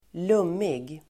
Ladda ner uttalet
lummig adjektiv (bildligt och vardagligt " lätt berusad"), thickly wooded , leafy Uttal: [²l'um:ig] Böjningar: lummigt, lummiga Definition: inbäddad i grönska leafy adjektiv, lövrik , bladrik , lummig
lummig.mp3